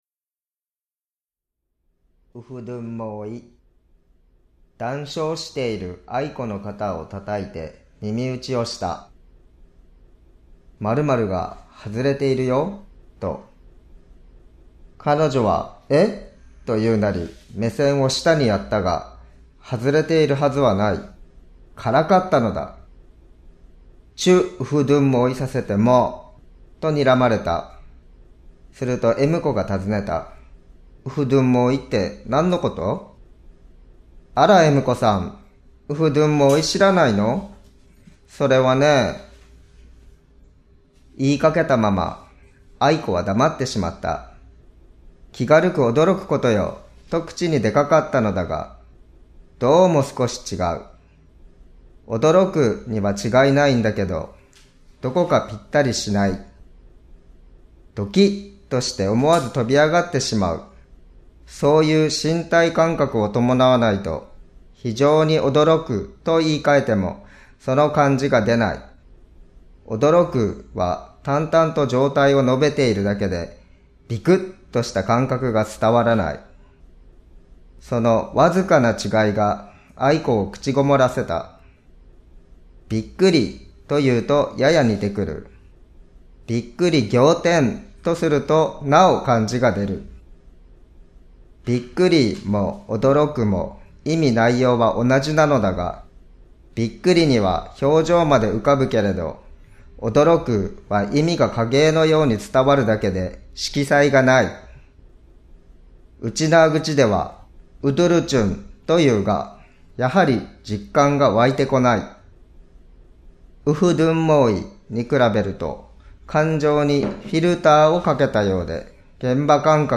[オーディオブック] うちなぁぐちフィーリング 「ウフドゥンモーイ」
老若男女、出身も様々な9人の読み手が「うちなぁぐち」の人気コラムを朗読
ときおり、昭和時代の懐かしいひびきも聴こえてくるかもしれません。
普段触れることのないみなさんも、どうぞ、ことばの響きやリズム、“沖縄的感覚”をお楽しみください。